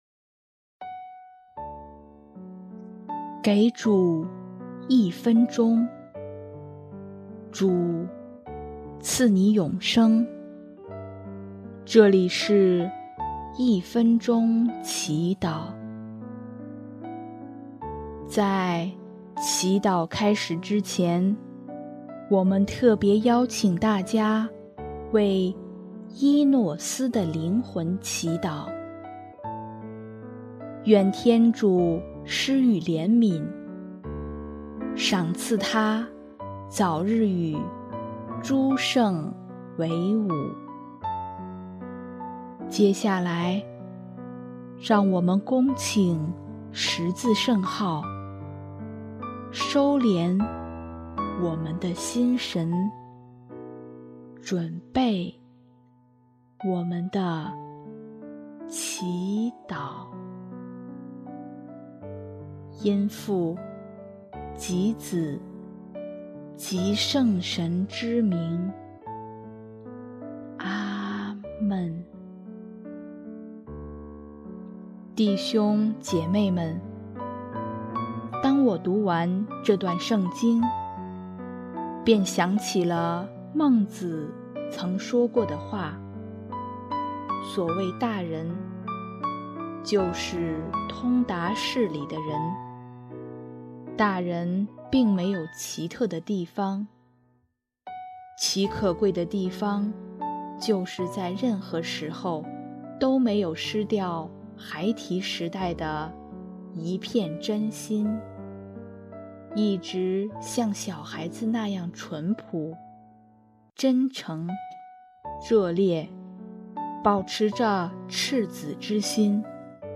音乐：第二届华语圣歌大赛参赛歌曲《慈爱的目光》（依搦斯的灵魂）